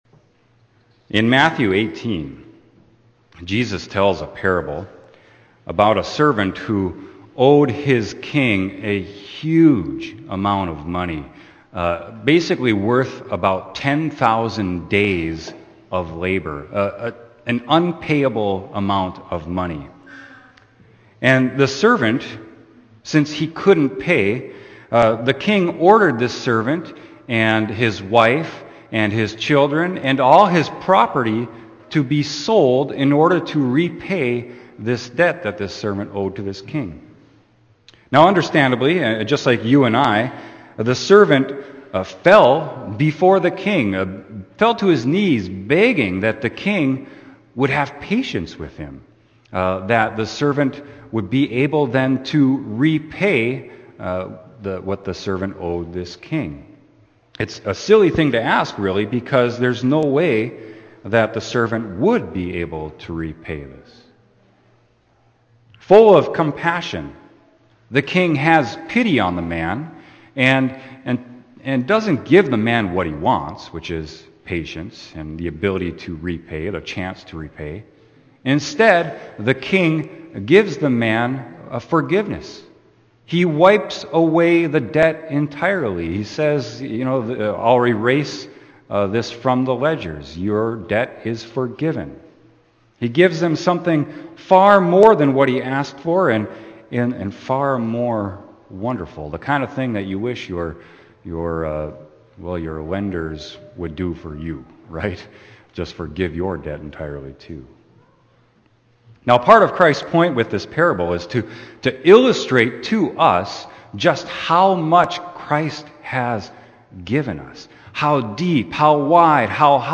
Sermon: Romans 12.9-21